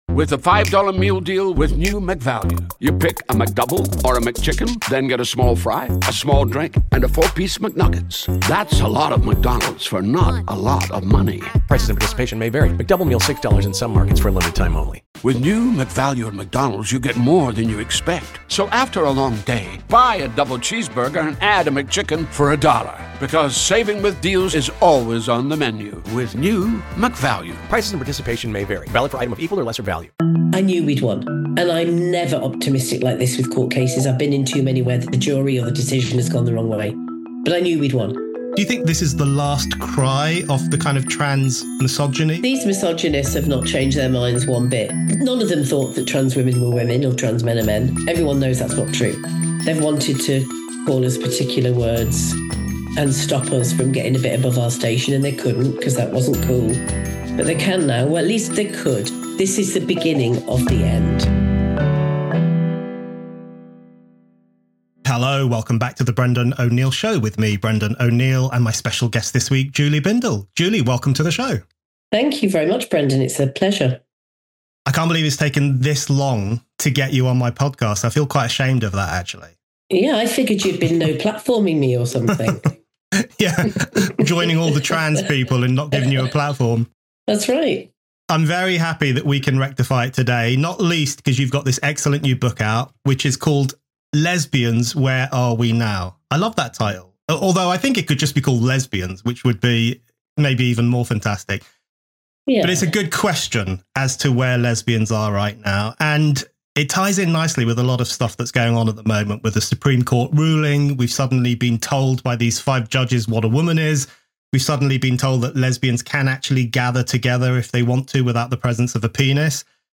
Julie Bindel – author of Lesbians: Where Are We Now? – is the latest guest on The Brendan O’Neill Show . Julie and Brendan discuss why the Supreme Court’s gender ruling is so vital for lesbians, why gay rights and trans rights have nothing in common, and why being a lesbian used to be fun.